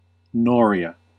Ääntäminen
Synonyymit water wheel Ääntäminen US UK : IPA : /ˈnɔː.ɹɪə/ US : IPA : /ˈnɔɹ.i.ə/ Haettu sana löytyi näillä lähdekielillä: englanti Määritelmät Substantiivit A water wheel with attached buckets , used to raise and deposit water.